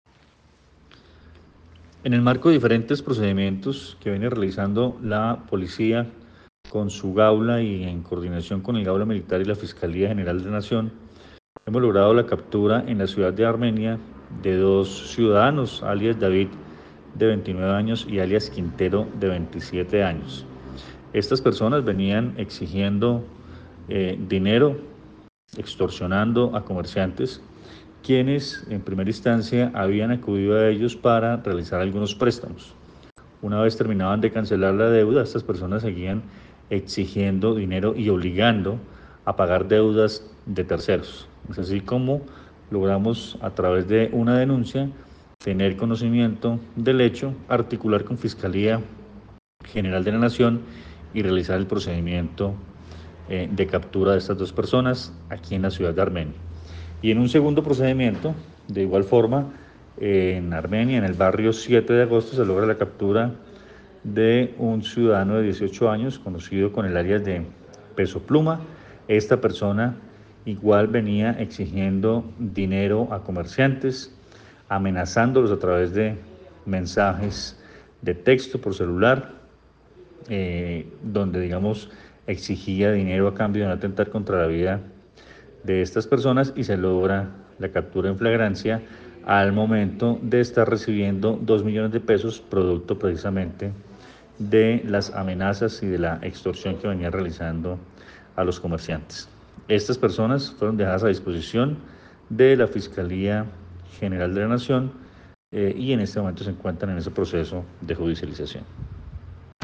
Coronel Luis Fernando Atuesta sobre caso de extorsiones